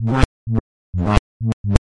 基调舞a2 f2 128 bpm fizzy bass
Tag: 最小 狂野 房屋 科技 配音步 贝斯 精神恍惚 舞蹈 俱乐部